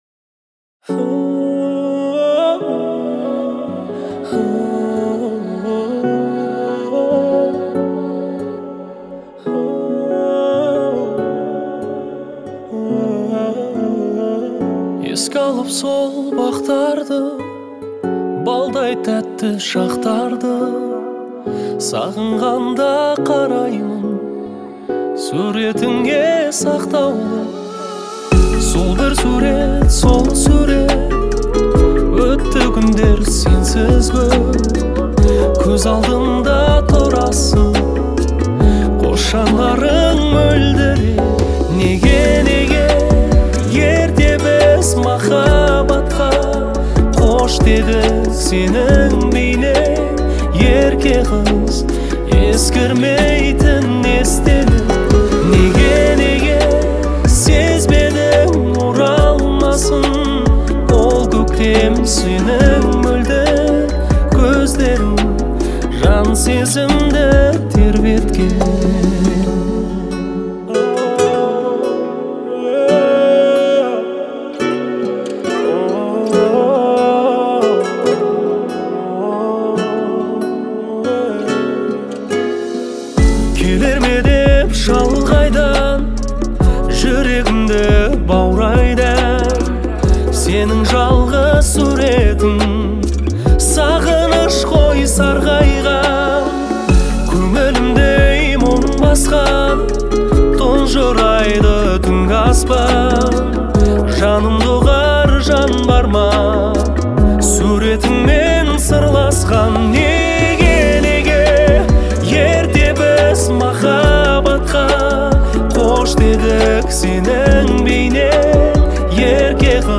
яркая и мелодичная композиция
относящаяся к жанру поп-музыки.
традиционные мелодии с современными ритмами